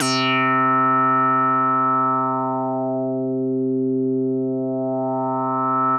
C4_raspy_synth.wav